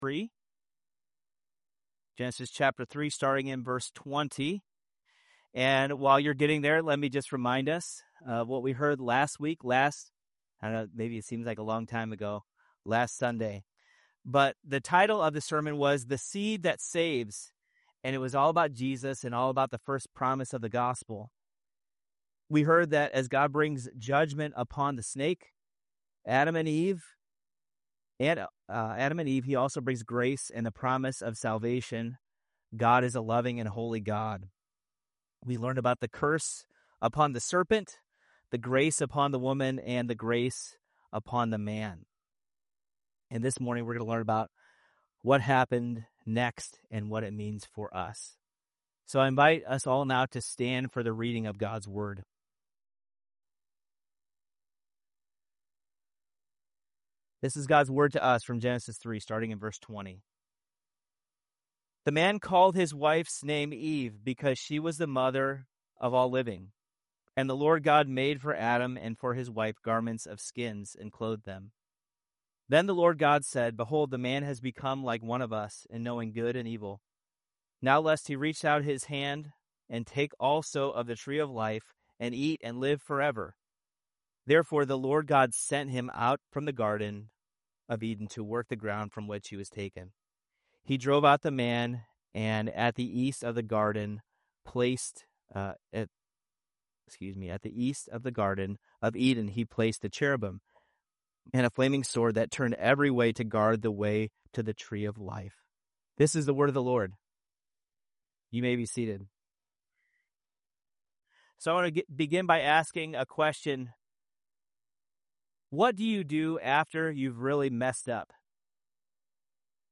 SERMON OUTLINE “Life After Death” Genesis 3:20-24 Big Idea: Because there is life after death, then God’s people walk by faith in a fallen world I. Believe instead of blame (v. 20) II.